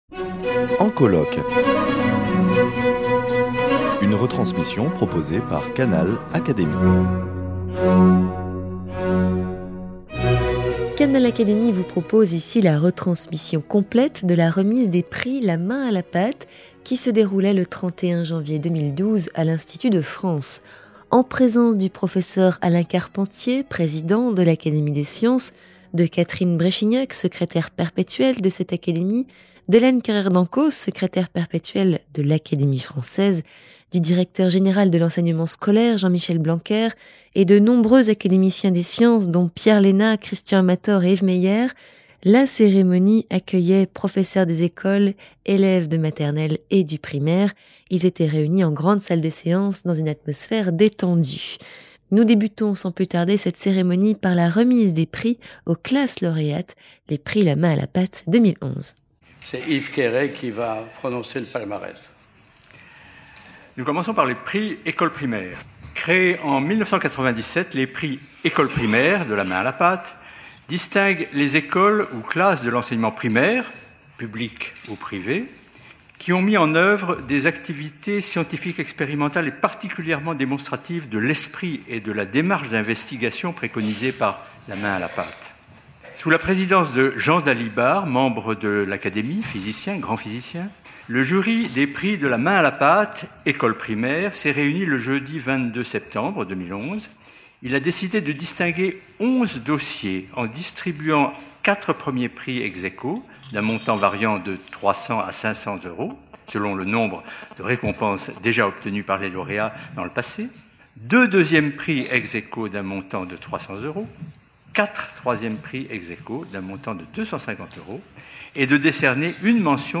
Retransmission complète de la remise des prix La main à la pâte qui se déroulait le 31 janvier 2012 à l’Institut de France.